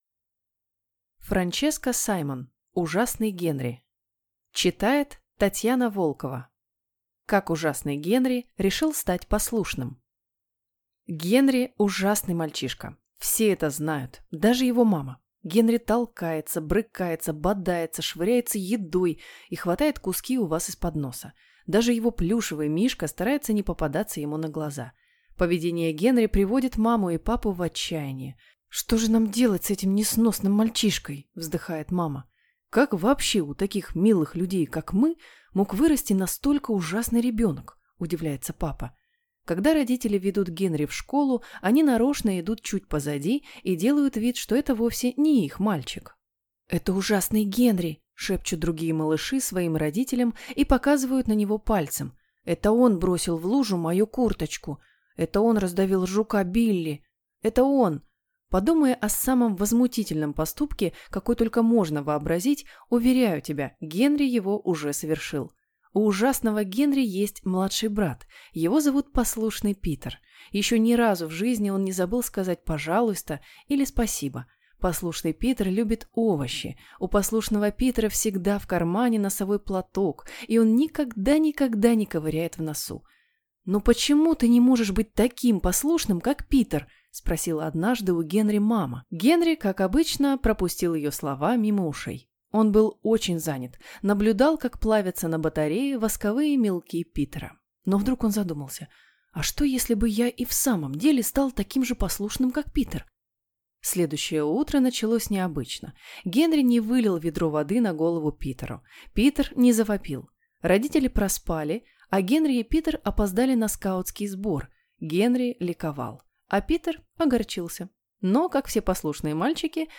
Аудиокнига Ужасный Генри | Библиотека аудиокниг